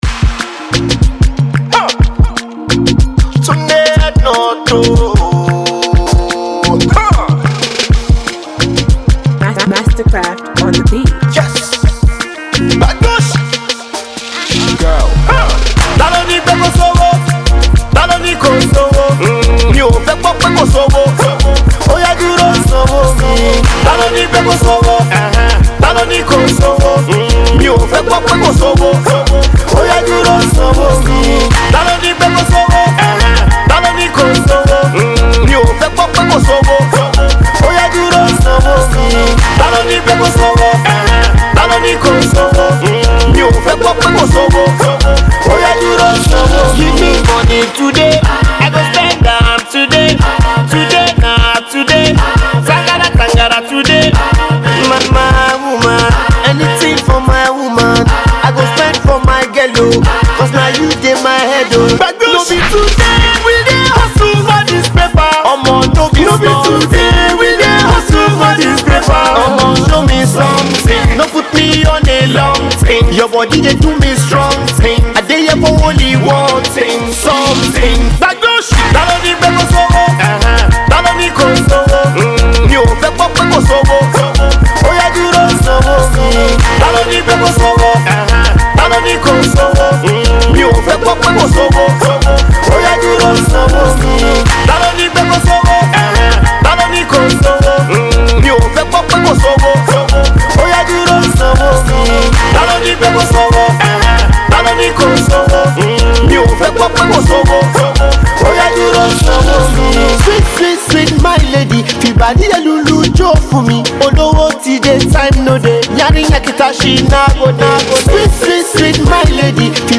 AudioPop